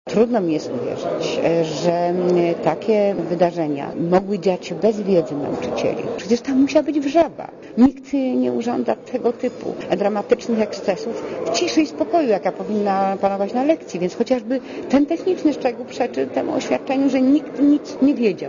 Dla Radia Zet mówi minister Łybacka (72 KB)